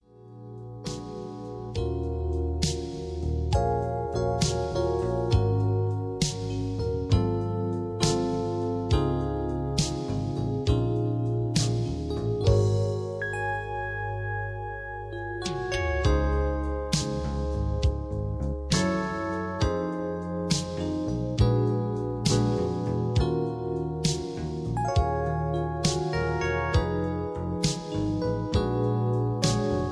(Key-Db) Karaoke MP3 Backing Tracks